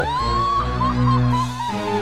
ojou laugh
Download ojou laugh Sound effect Button free on Sound Buttons
ojou_laugh.mp3